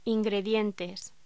Locución: Ingredientes
voz